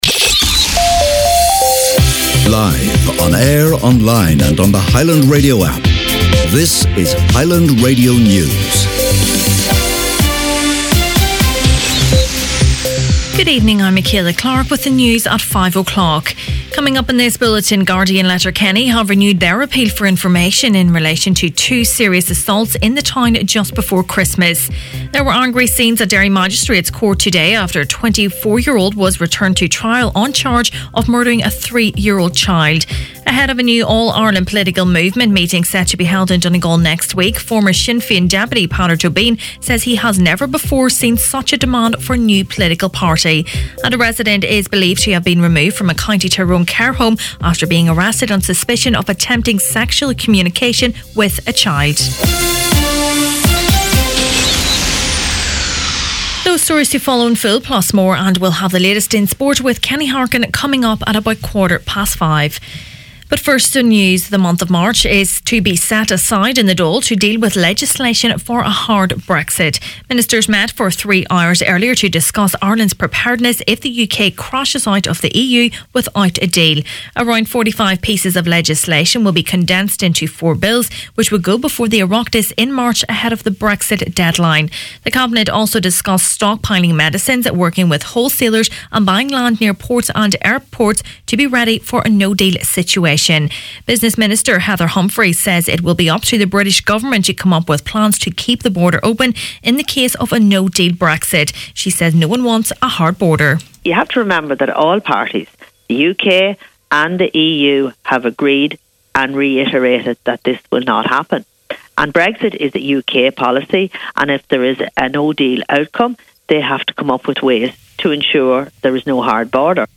Main Evening News, Sport and Obituaries Thursday January 3rd